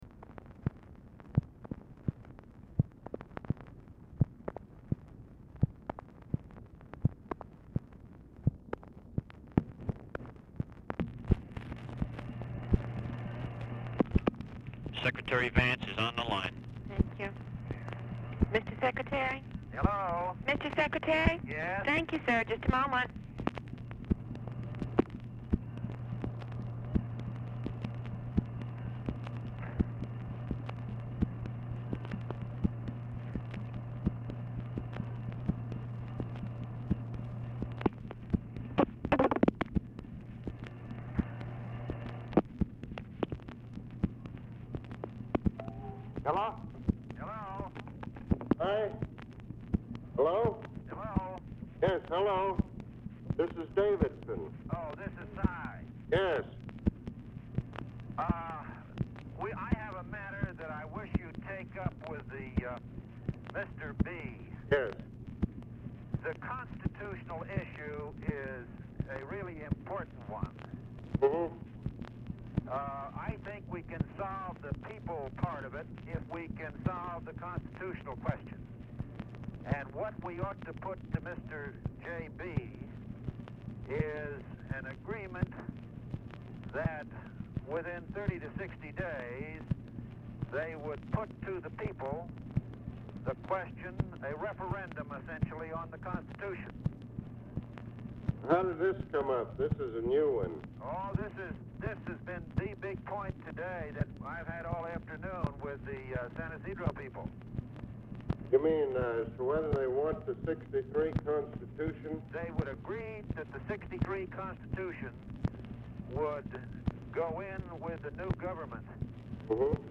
VANCE ON HOLD 0:41; LBJ ONLY ANSWERS TELEPHONE; POOR SOUND
Specific Item Type Telephone conversation